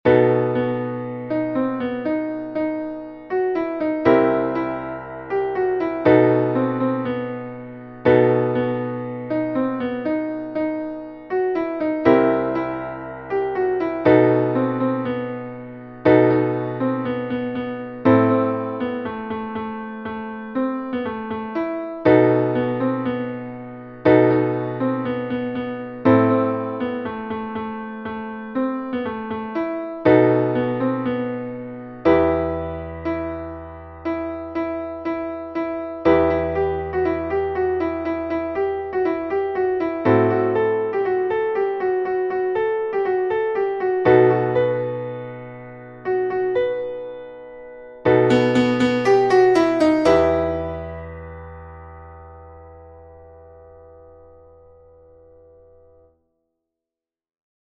Traditionelles Lied